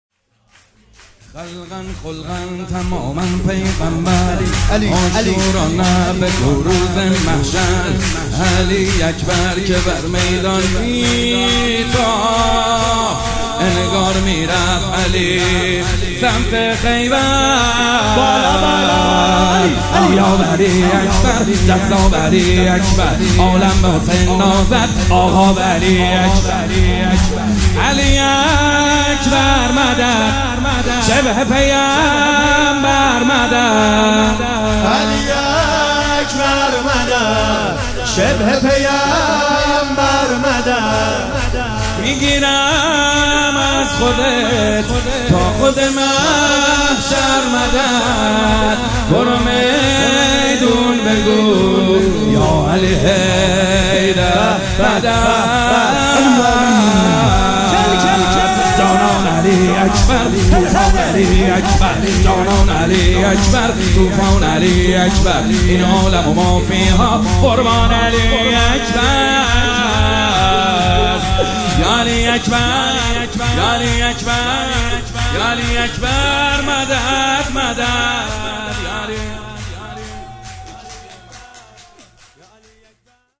شب ولادت علی اکبر - سرود